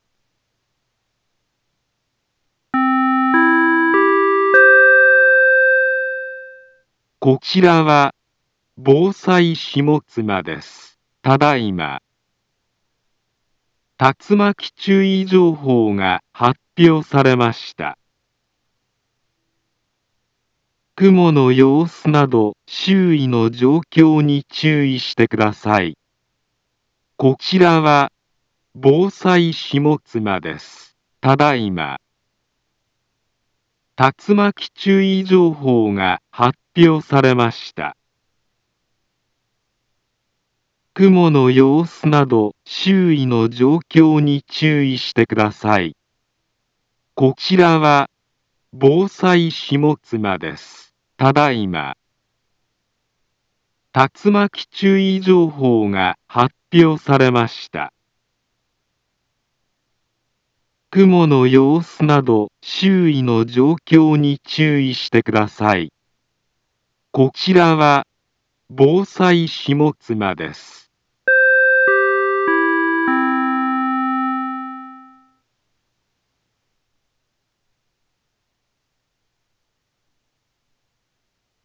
Back Home Ｊアラート情報 音声放送 再生 災害情報 カテゴリ：J-ALERT 登録日時：2023-05-22 19:05:28 インフォメーション：茨城県南部は、竜巻などの激しい突風が発生しやすい気象状況になっています。